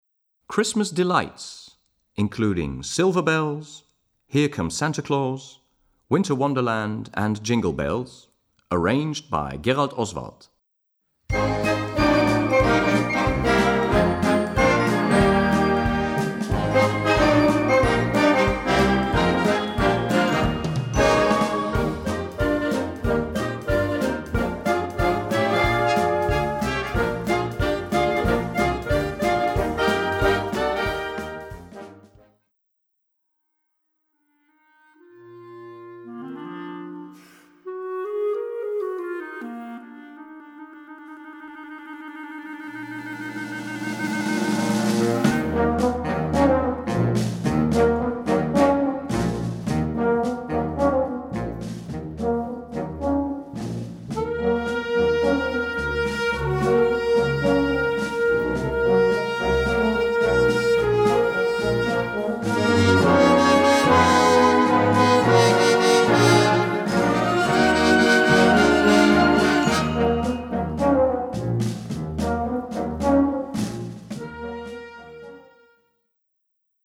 Gattung: Weihnachtsmusik
Besetzung: Blasorchester